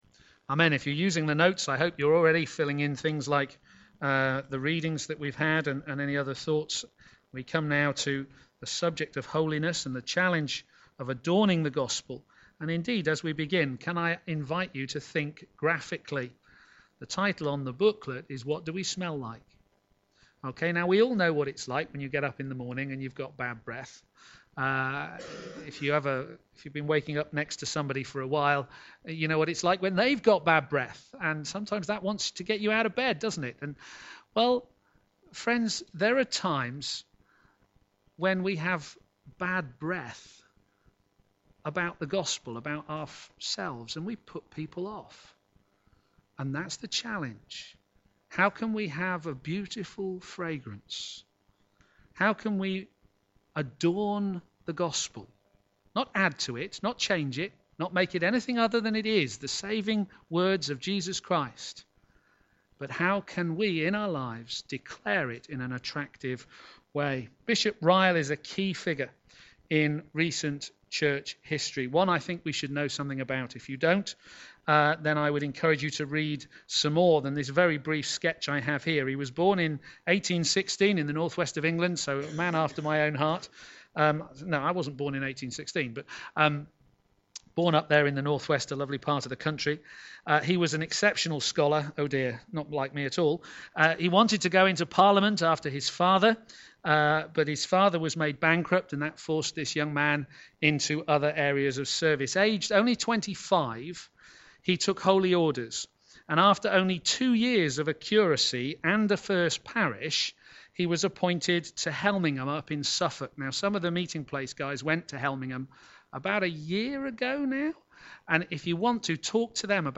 Media for a.m. Service on Sun 10th Apr 2016 10:30
Theme: Let us adorn the Gospel Sermon